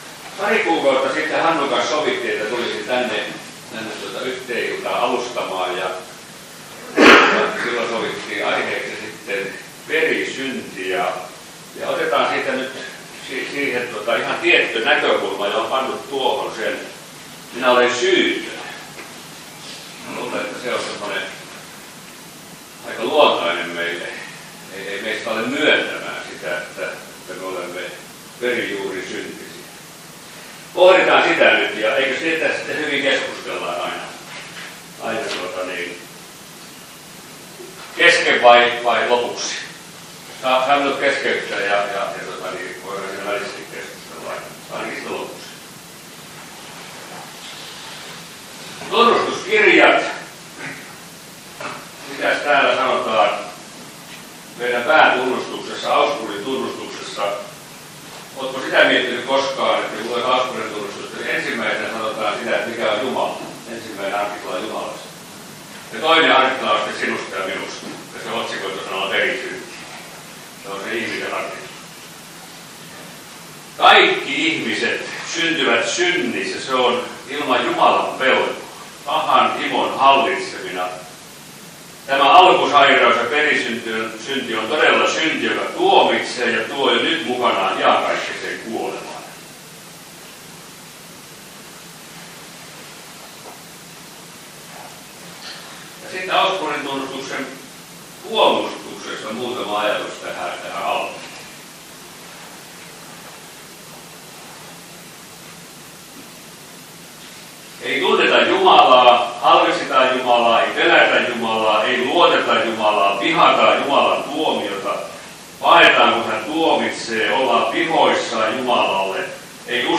Kokoelmat: Seinäjoen Hyvän Paimenen kappelin saarnat